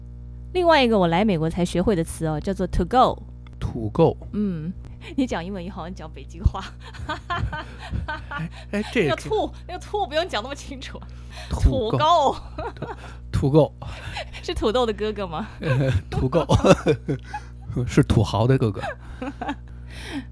上面三段就是被美女主播无情删除的。
Always_laugh_at_me.mp3